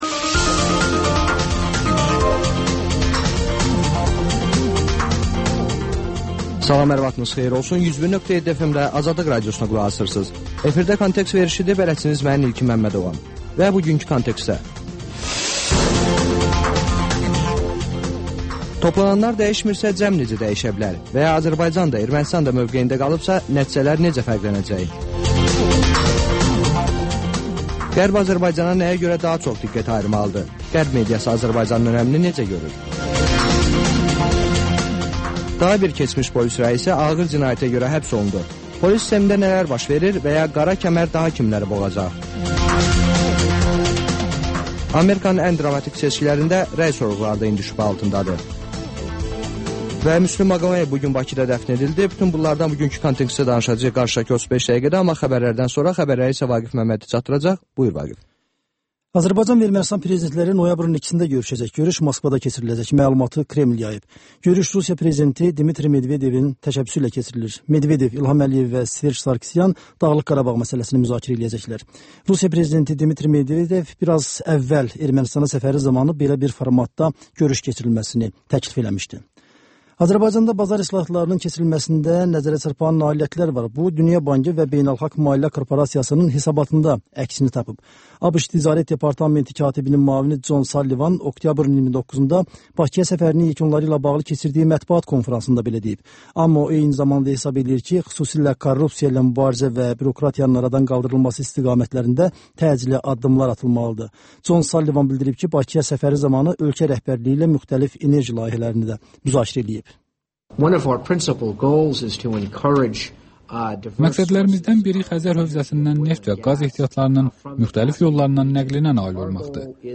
Xəbərlər, müsahibələr, hadisələrin müzakirəsi, təhlillər, daha sonra 14-24: Gənclər üçün xüsusi veriliş